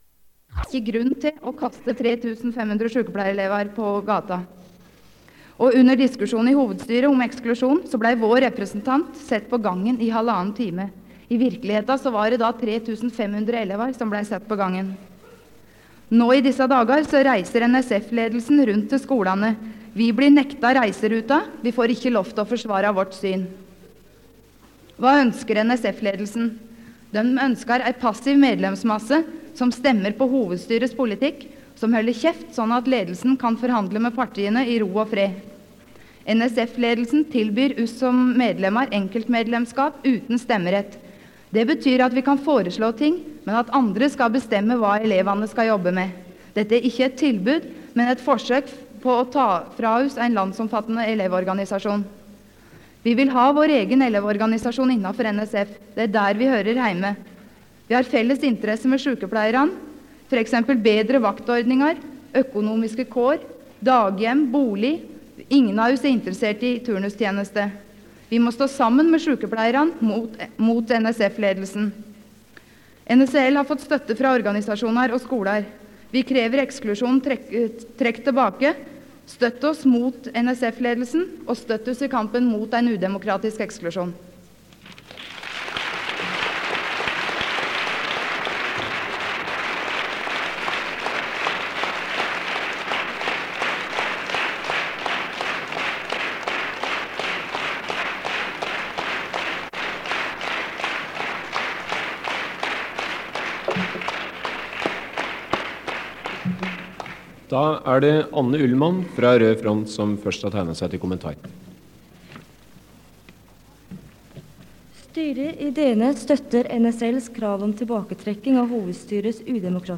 Det Norske Studentersamfund, Generalforsamling, 07.05.1977
Generalforsamling